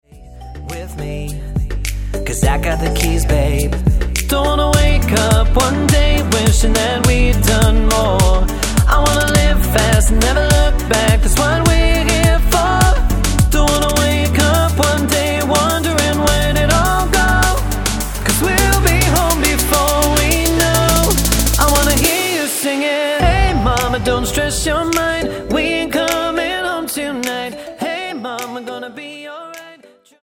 Tonart:Bm Multifile (kein Sofortdownload.
Die besten Playbacks Instrumentals und Karaoke Versionen .